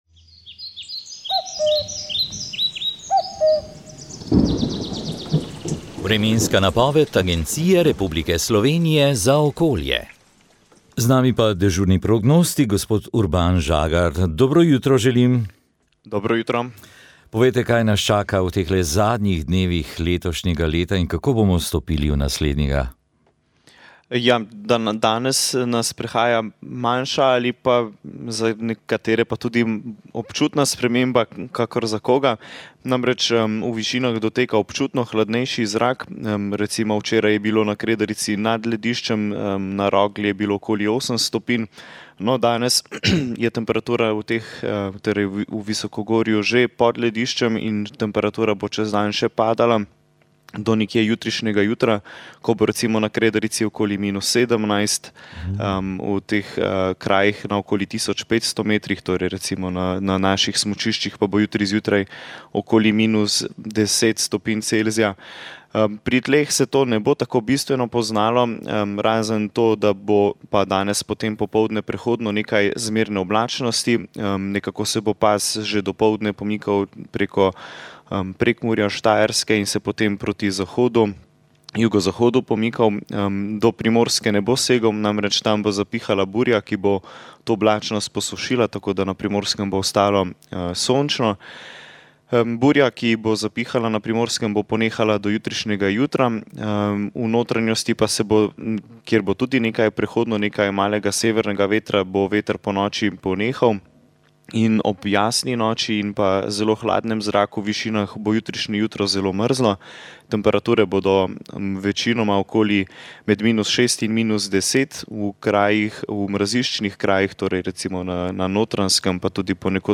Rožni venec